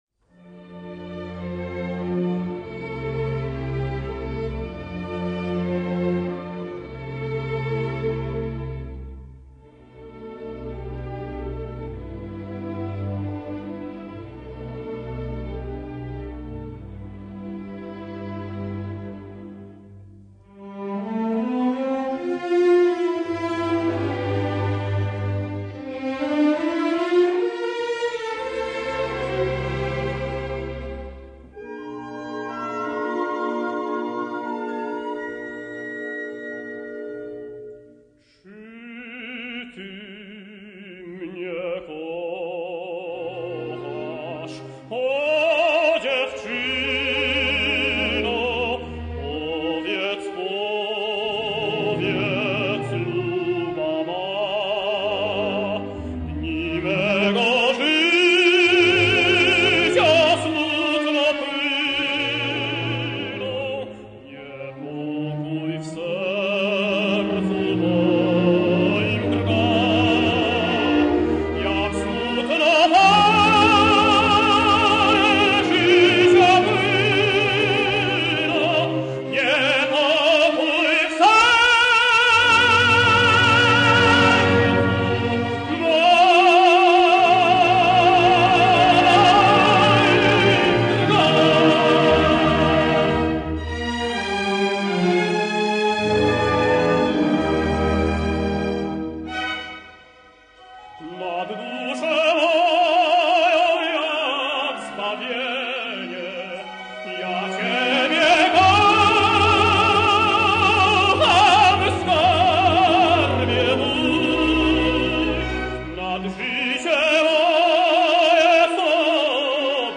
Bogdan Paprocki singsLegenda Bałtyku:
paprockilegendabaltykuariadomanaact2.rm